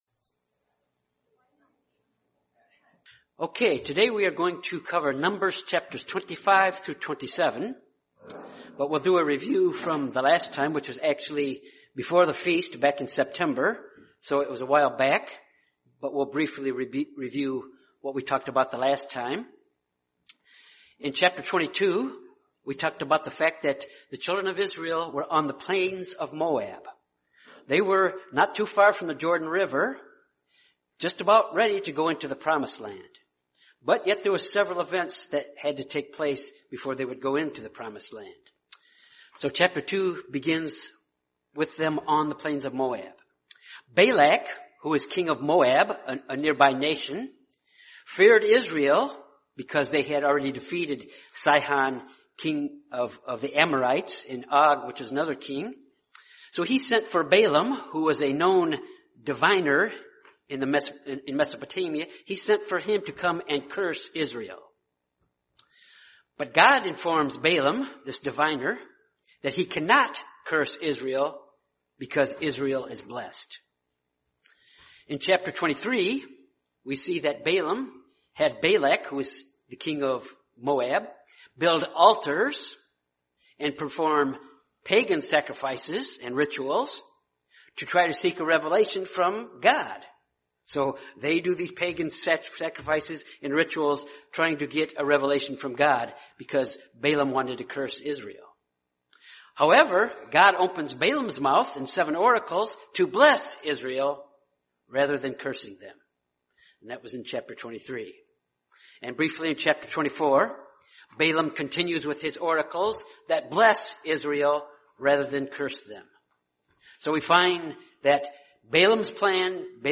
Given in Jonesboro, AR Little Rock, AR Memphis, TN